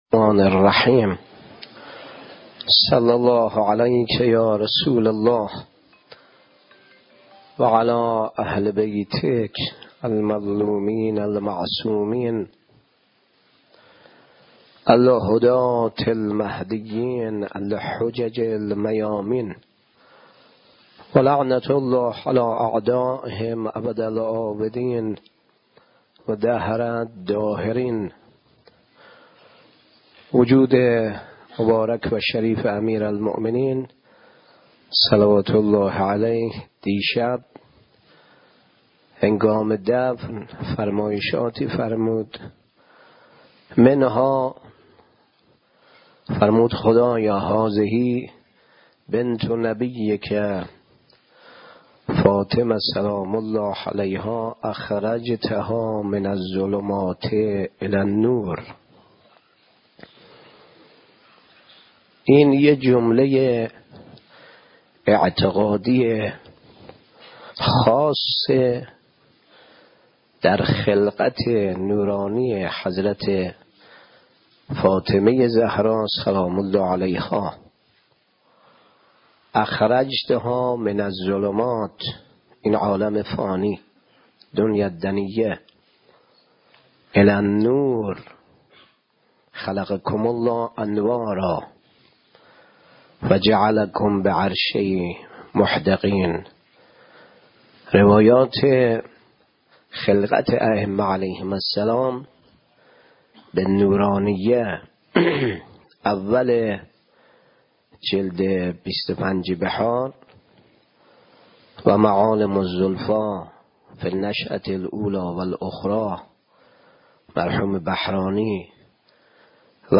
1 بهمن 97 - بیت مرحوم آیةالله بروجردی - سخنرانی